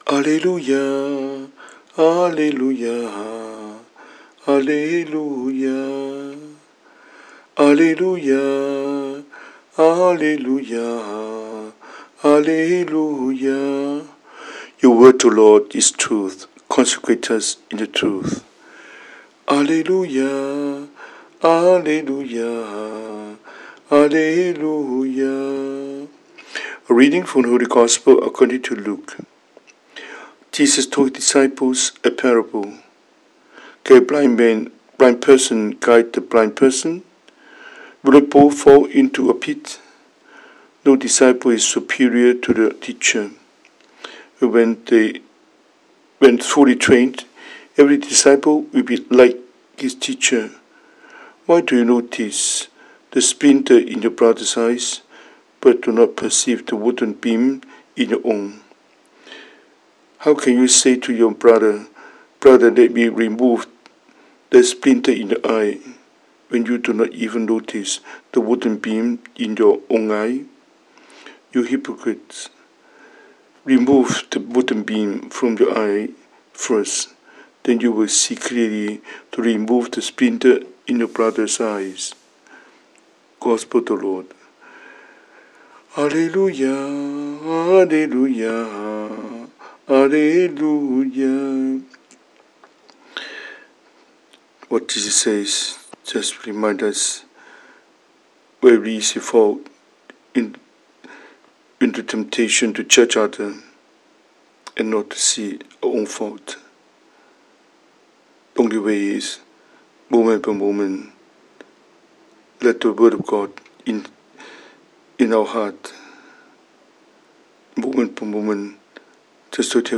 Cantonese Homily,